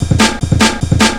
FILL 5    -L.wav